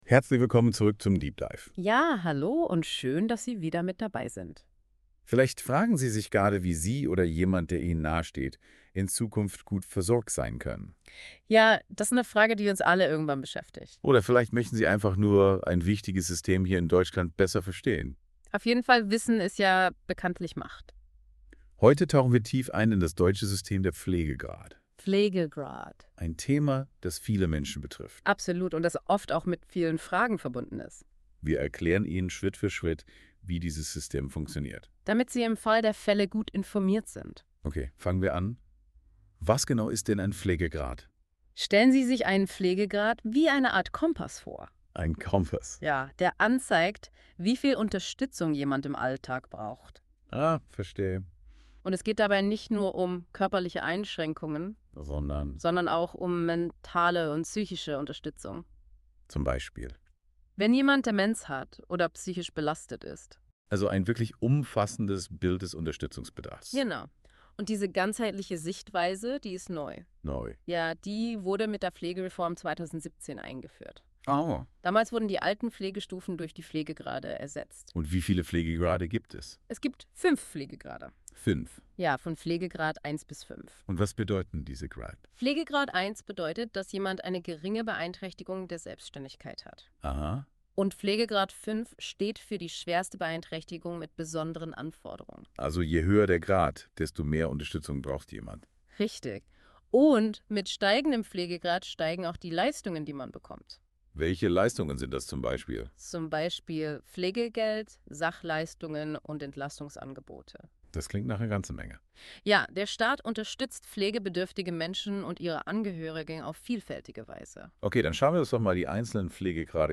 Klar. Beruhigend. Menschlich.